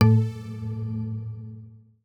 UIClick_Long Modern Ringing 02.wav